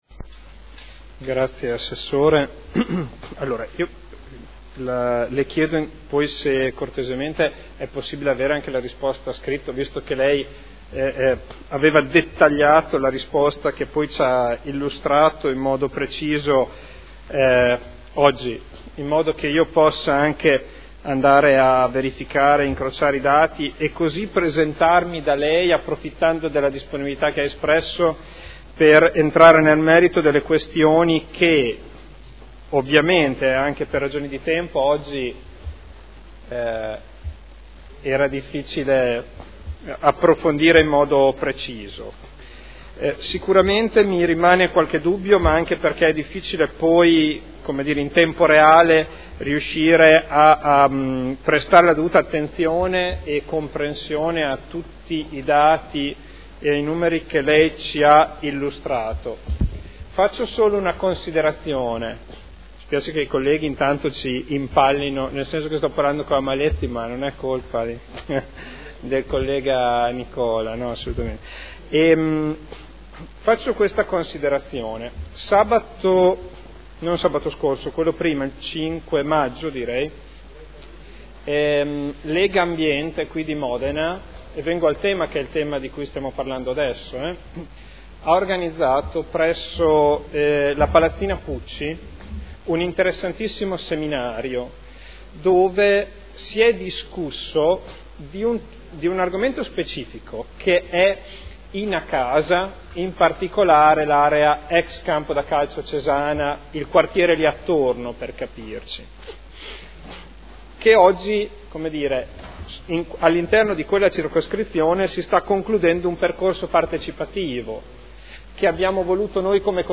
Seduta del 14/05/2012. Conclude interrogazione del consigliere Ricci (Sinistra per Modena) avente per oggetto: Definizione del Bilancio preventivo 2012, risorse per la realizzazione di alloggi ERP”